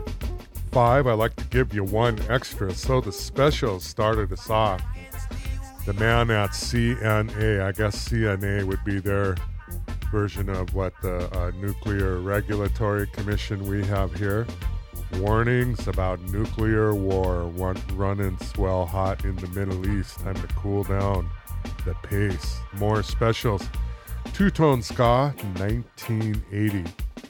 anti-war ska